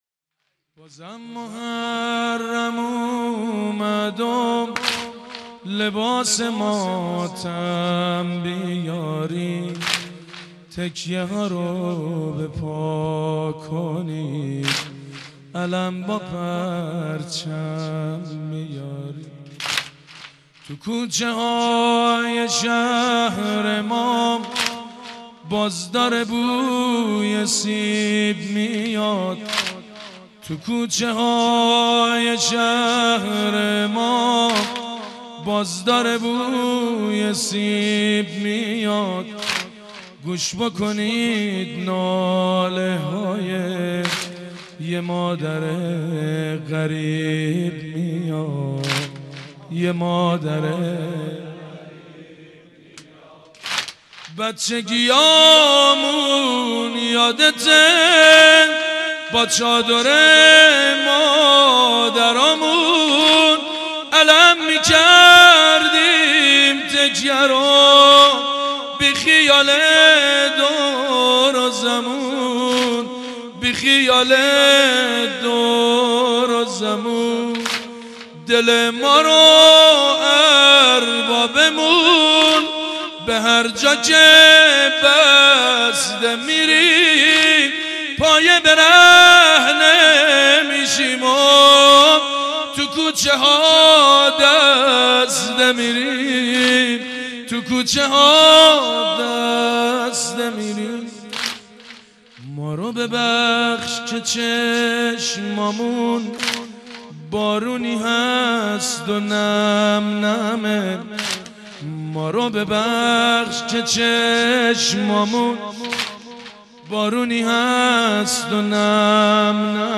مداحی محرم